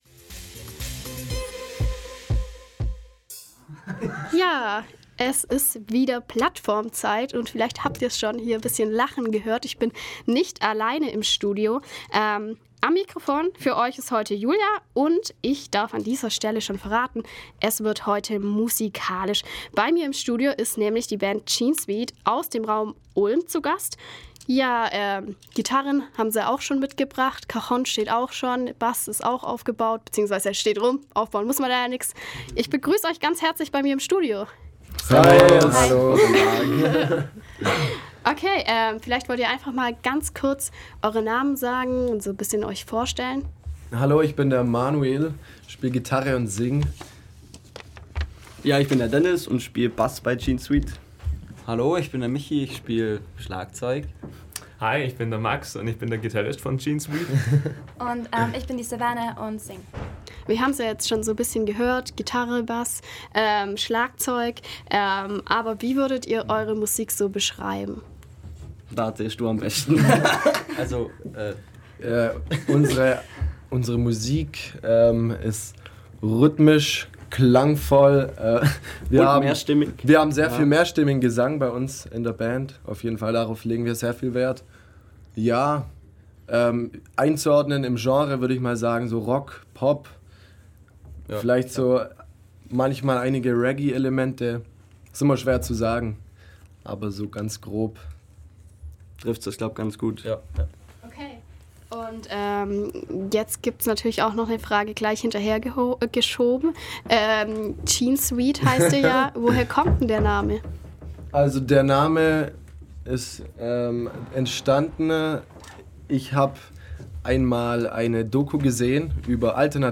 Melodien die sofort ins Ohr gehen und ein schwungvoller Rhythmus beschreiben wohl am ehesten was die Band JeanSweed ausmacht.